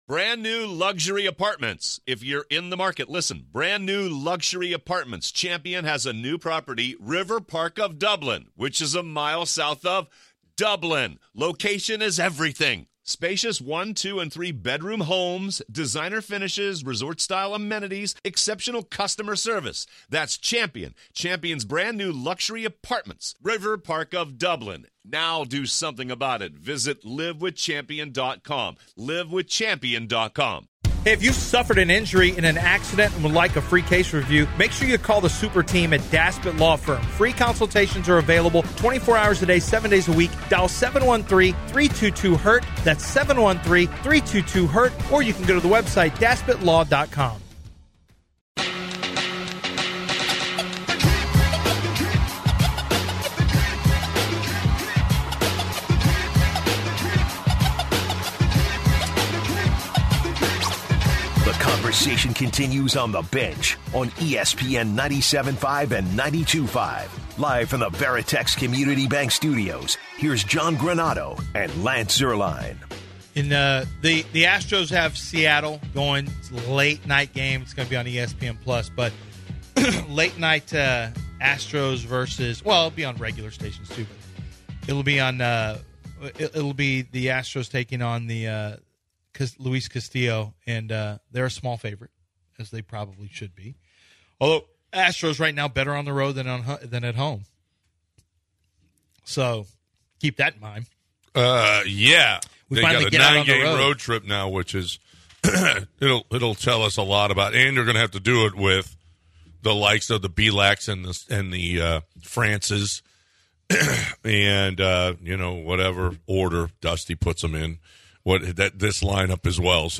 Also, We hear from Tank Dell, who shares how his relationship with Quarterback with CJ Stroud developed during the NFL Combine. In addition, Jimmie Ward spoke with the media and said that it's time for the Texans to create their own culture.